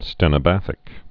(stĕnə-băthĭk)